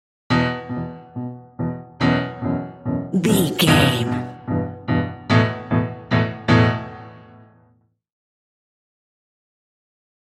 Building Tension Scene Change.
In-crescendo
Thriller
Aeolian/Minor
ominous
suspense
eerie
stinger
short music instrumental